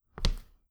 hard-footstep4.wav